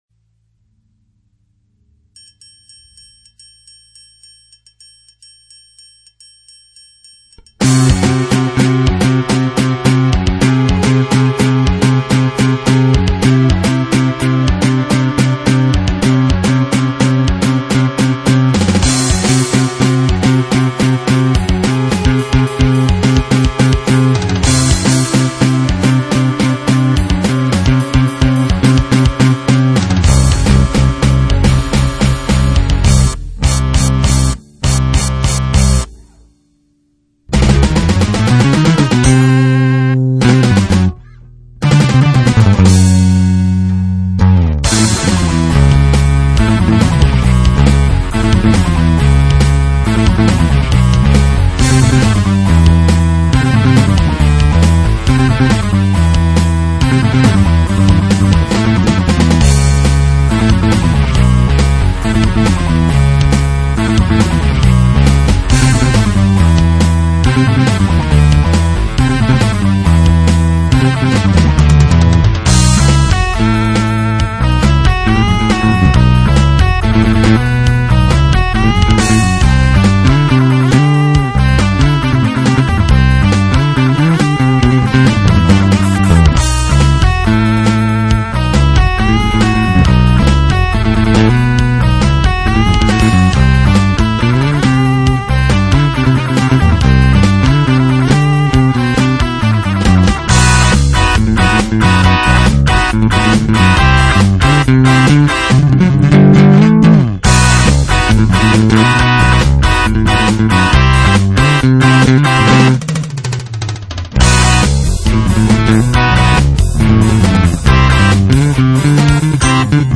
악보대로 백그라운드 녹음을 해 줄…
노래가 안되니… 연주곡이다…
녹음한 파트는 베이스 기타다… 그냥 둥둥둥 소리나는 거다…
악세서리 없이 그냥 잭만 MRS-4에 직접 연결했다.
그러고 보니 모르스부호 같기도 하다…
연주곡이다… 끝까지 들어도 노래가사 안나온다… ㅠ.ㅠ
그렇지만 파일크기 관계로 30M –> 8M –> 4M –> 2M(64Bit)로 줄이는 과정에서 잡음도 많다…